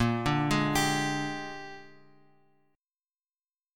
Bb13 Chord
Listen to Bb13 strummed